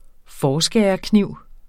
Udtale [ ˈfɒːˌsgεːʌ- ]